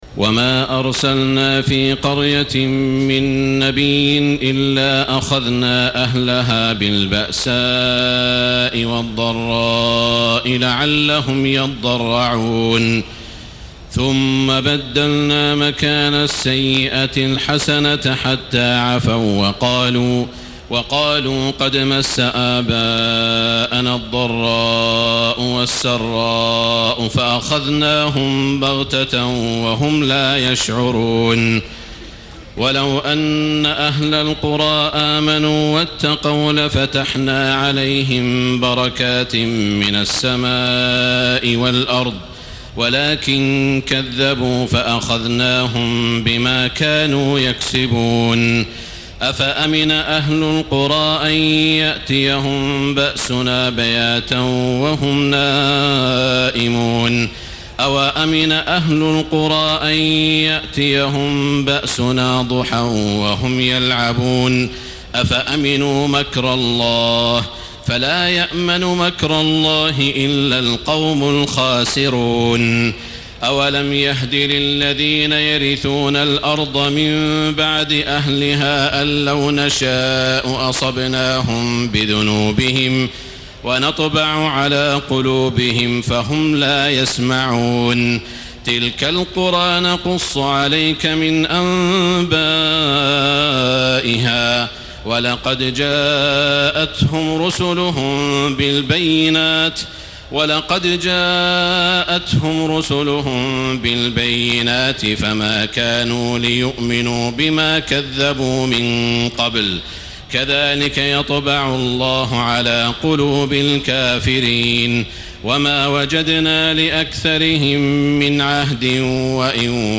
تهجد ليلة 29 رمضان 1434هـ من سورة الأعراف (94-188) Tahajjud 29 st night Ramadan 1434H from Surah Al-A’raf > تراويح الحرم المكي عام 1434 🕋 > التراويح - تلاوات الحرمين